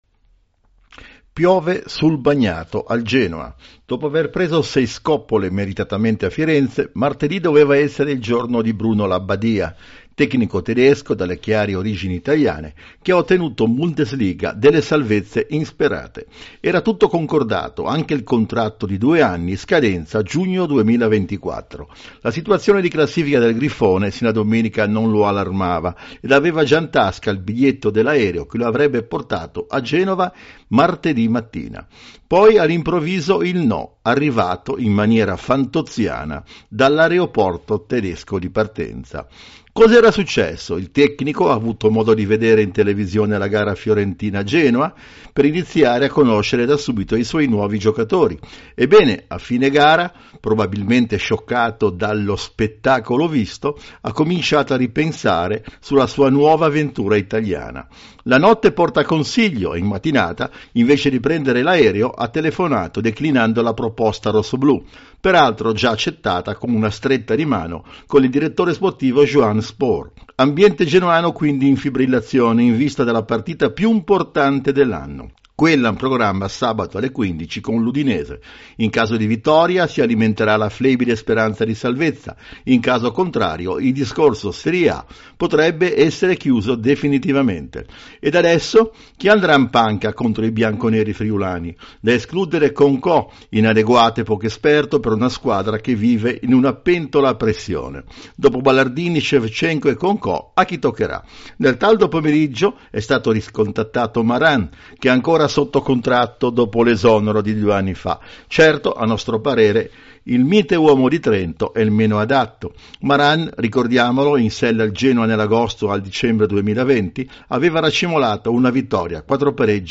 L’audio commento sull’imbarazzante caso-allenatore